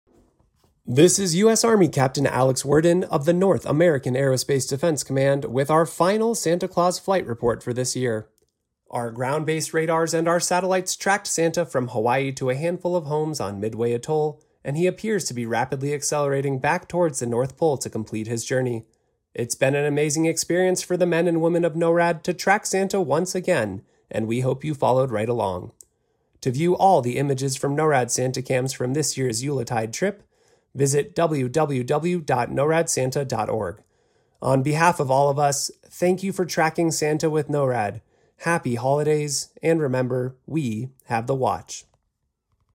NORAD Tracks Santa radio update to be aired at 3am MTS on December 24, 2025.